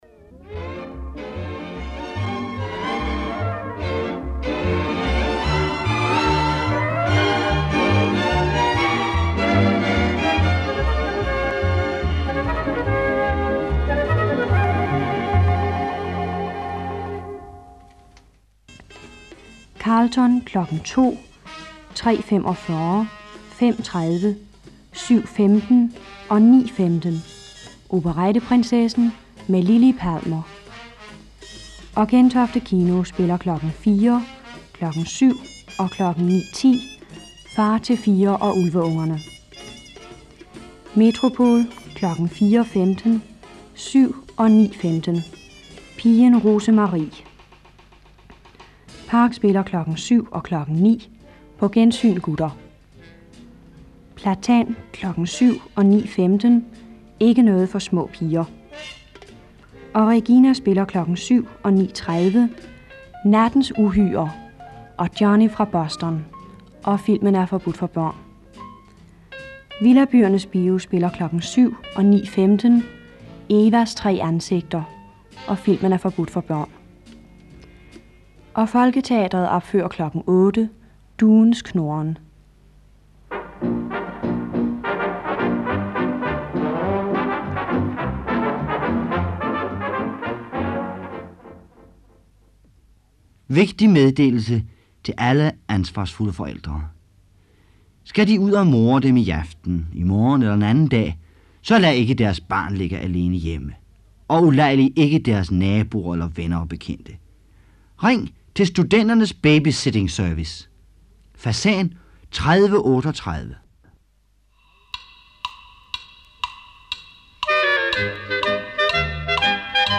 Programmet sändes som sista programpunkt i Mercur den 11 april 1960..